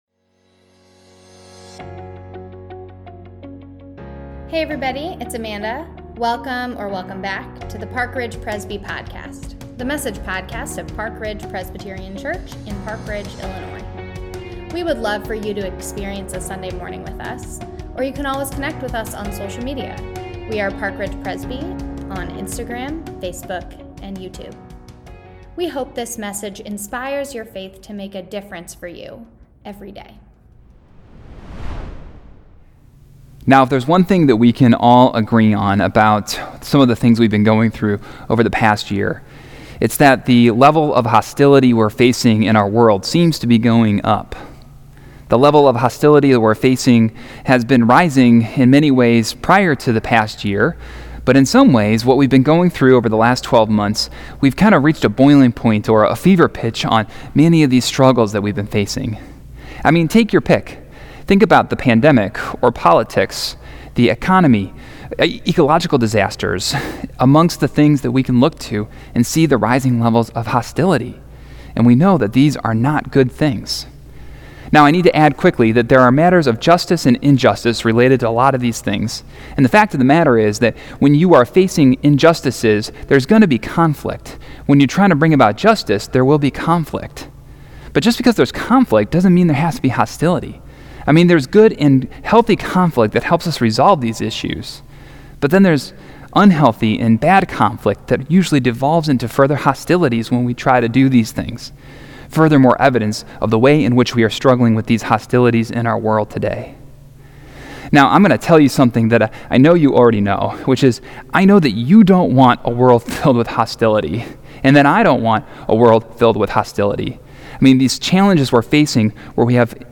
Easter-Sermon.mp3